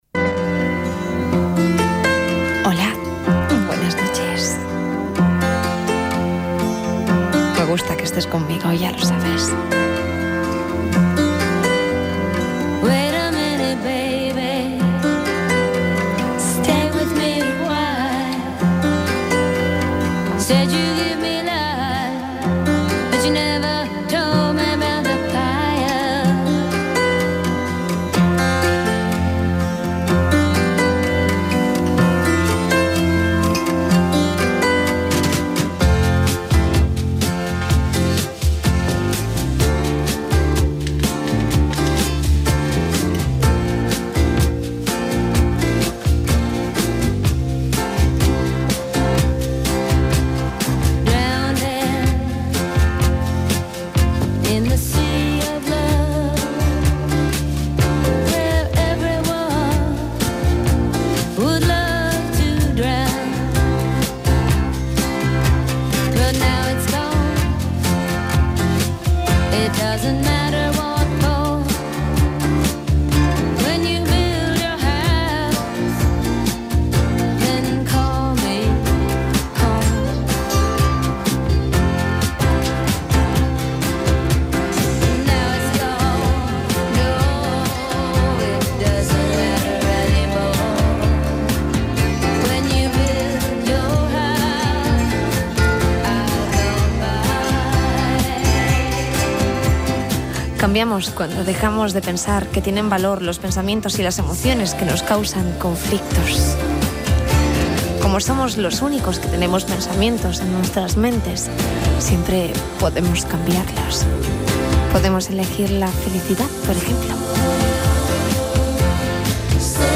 Salutació inicial, tema musical, comentari sobre els pensaments i tema musical
Musical
FM